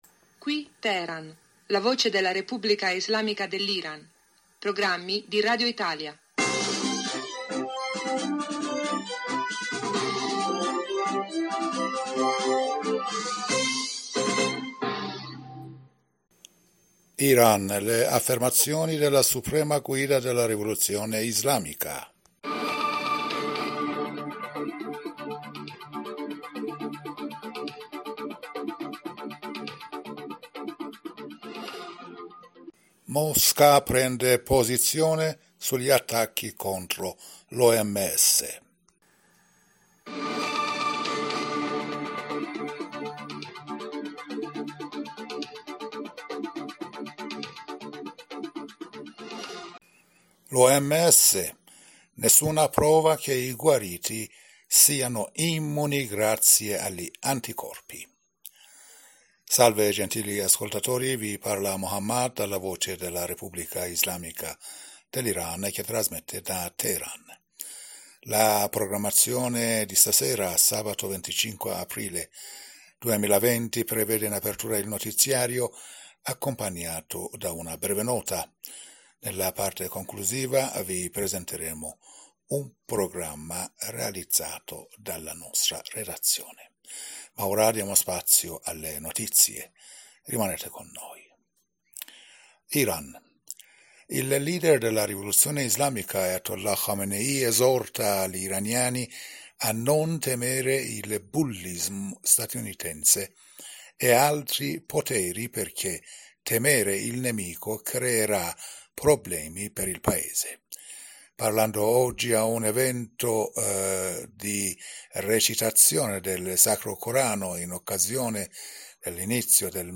Giornale radio 25 aprile 2020 sera